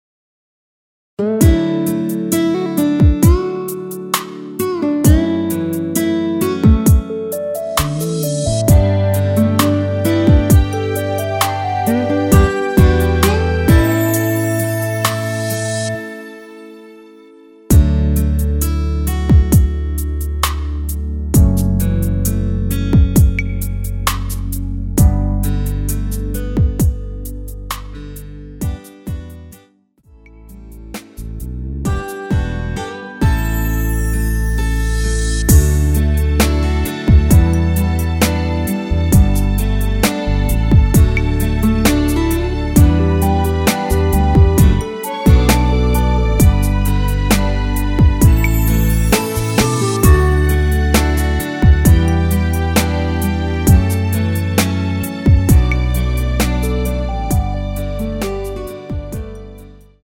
원키 MR입니다.
앞부분30초, 뒷부분30초씩 편집해서 올려 드리고 있습니다.
중간에 음이 끈어지고 다시 나오는 이유는